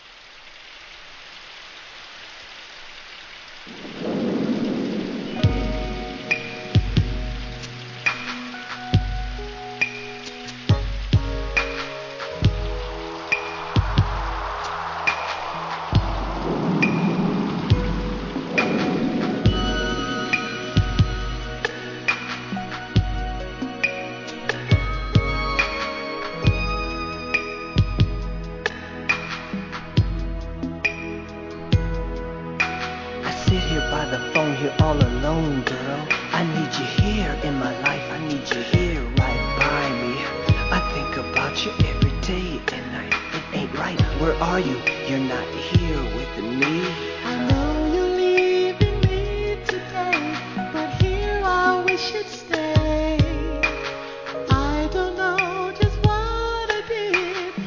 HIP HOP/R&B
冒頭から甘〜いRAPを絡めたファルセット・ヴォーカルの激メロ〜作!!